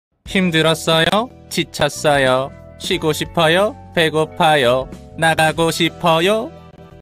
exhausted tired want rest hungry want out Meme Sound Effect
Category: Games Soundboard